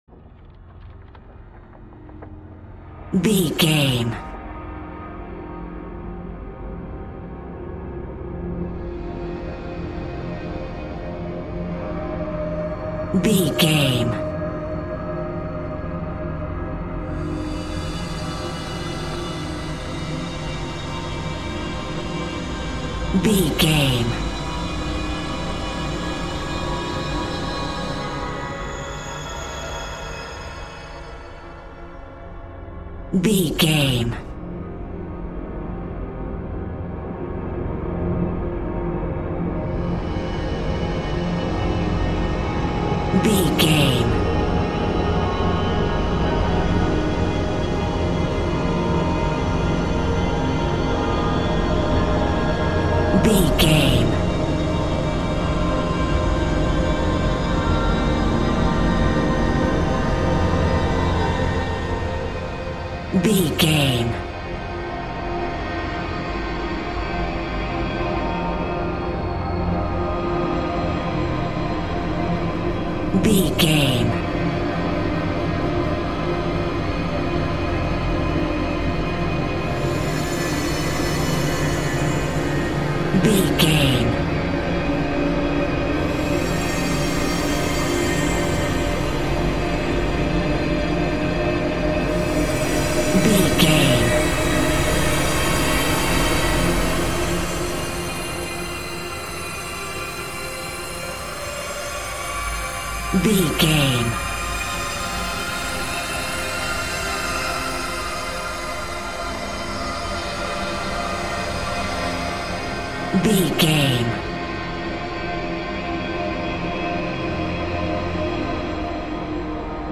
Scary Ripple Atmosphere.
In-crescendo
Atonal
Slow
ominous
eerie
Horror Pads
Horror Synth Ambience